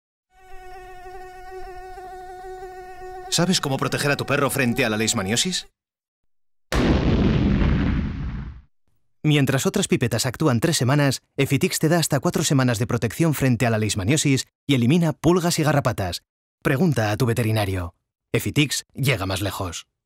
Native Voice Samples
Explainer Videos
High
AccurateArticulateAssuredAuthoritativeBelievableCalmConversationalCorporateDynamicEngagingKnowledgeableRelatable